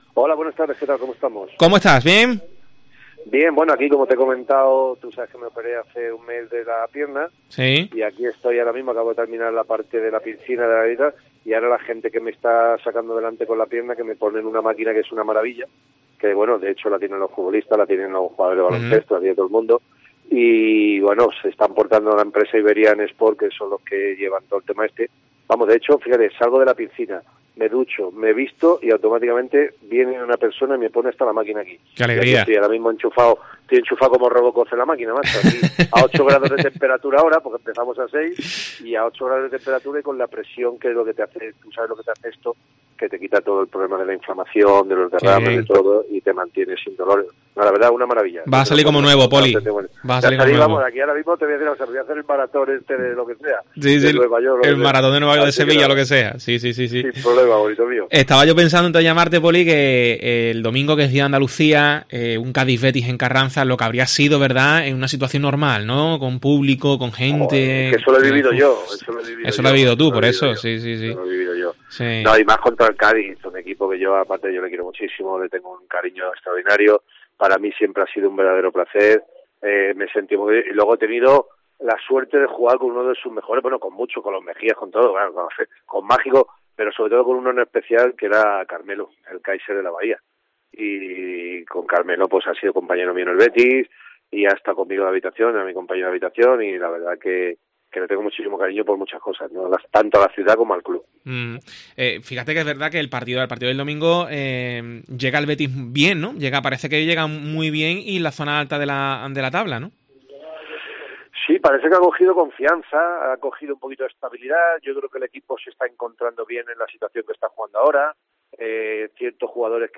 Comentarista de Tiempo de Juego y exjugador del Betis analiza un partido "muy complicado y el que no veo un claro favorito"